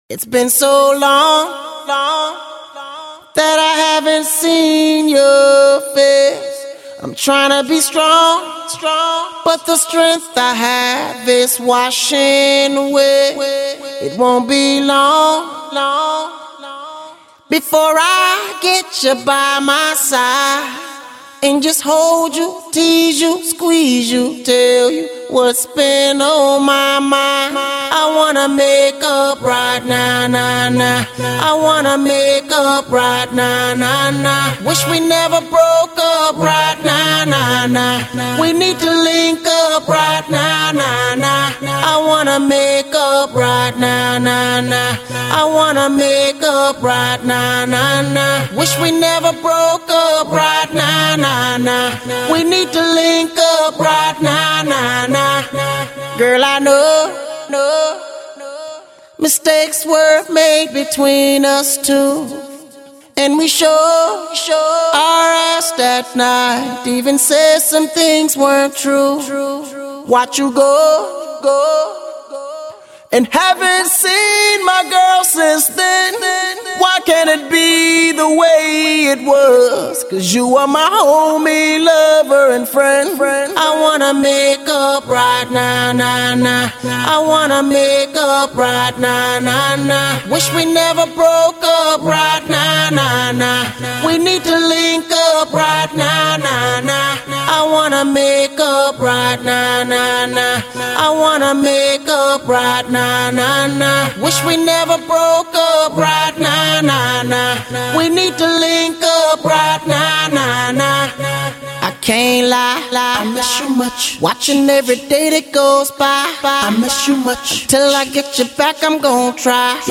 Скачать Зарубежные акапеллы [150]